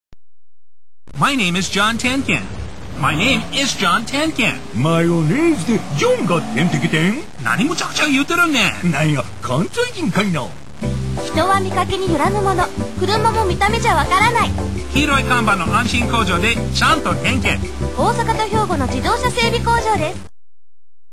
◆リスナーの耳に残る印象強さをプランの柱に、オモシロい外人との言葉遊び的かけあいで、　安心工場でクルマの“テンケン”というフレーズに絞り込んでアピールするシリーズです。
外人(英語の時間のようにリピート)